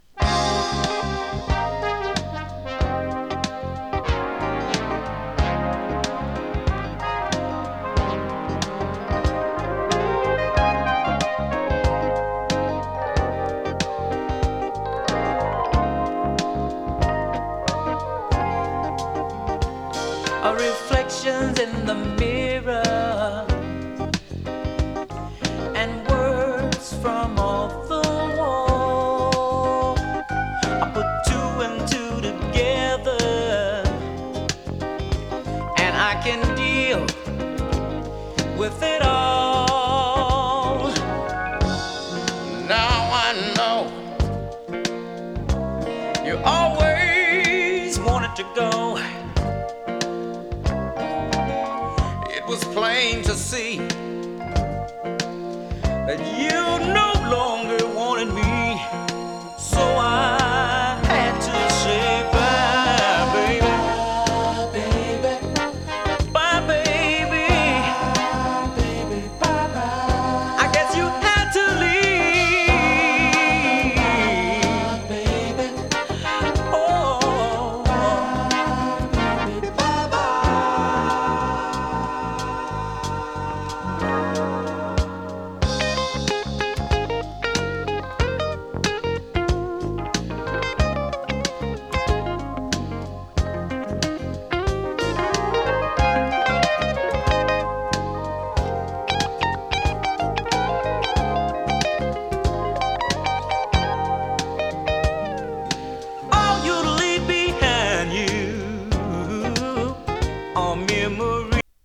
キャッチーディスコ ファンク ダンクラ 哀愁メロウ AOR